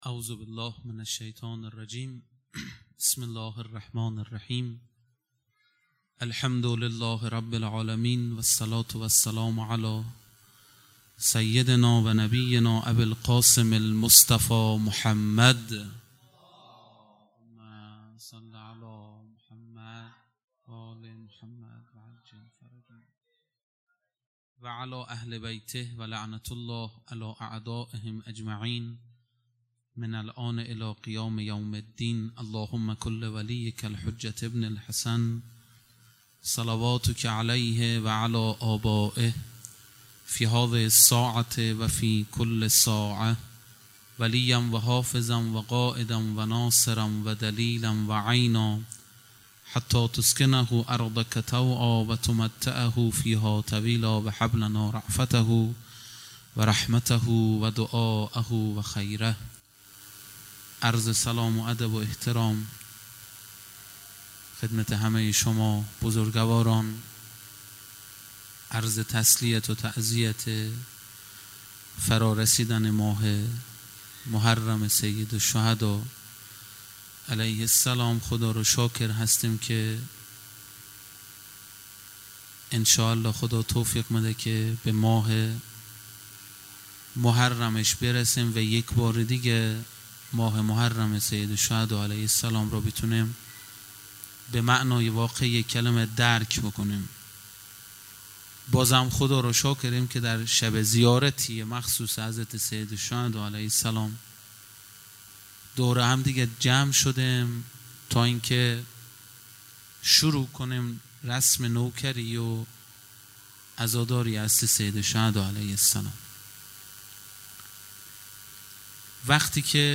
خیمه گاه - هیئت بچه های فاطمه (س) - سخنرانی | 6 مرداد 1401